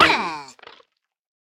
Minecraft Version Minecraft Version 1.21.5 Latest Release | Latest Snapshot 1.21.5 / assets / minecraft / sounds / mob / armadillo / death3.ogg Compare With Compare With Latest Release | Latest Snapshot
death3.ogg